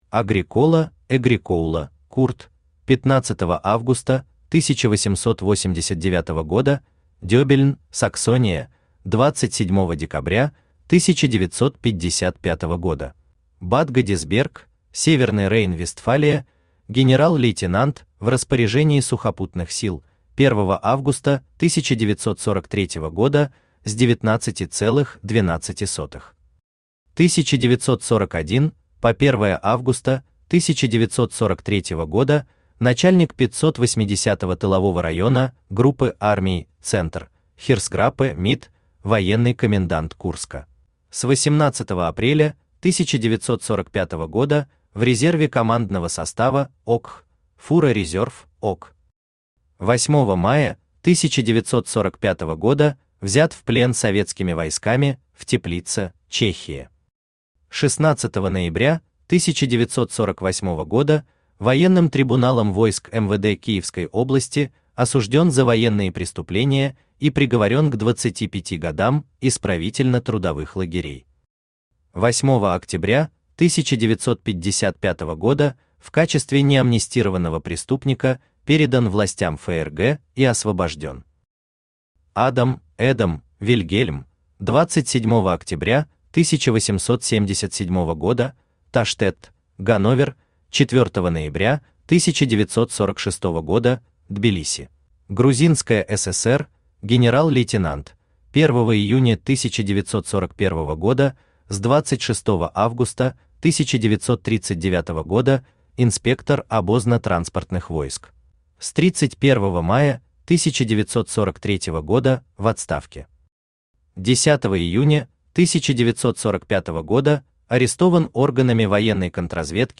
Аудиокнига Пленные генералы Гитлера Восточный фронт | Библиотека аудиокниг
Aудиокнига Пленные генералы Гитлера Восточный фронт Автор Денис Соловьев Читает аудиокнигу Авточтец ЛитРес.